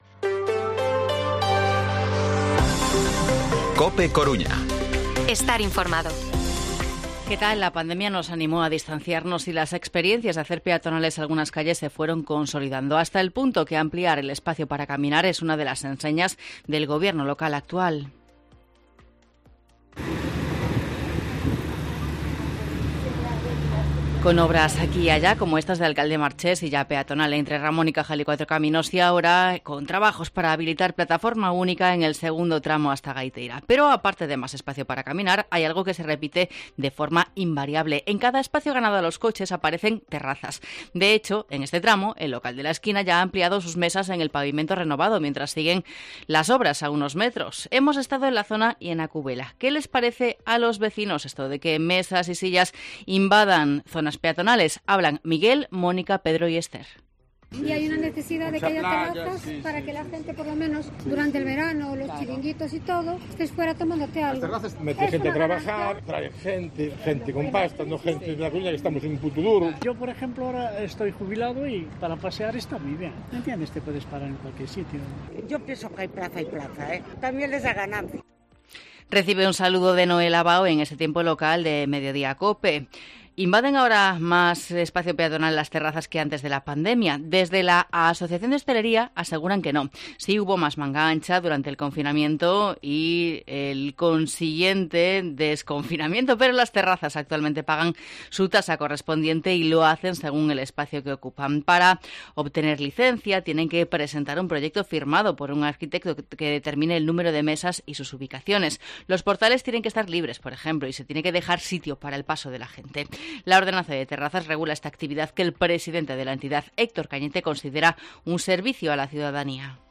Informativo Mediodía COPE Coruña jueves, 30 de marzo de 2023 14:20-14:30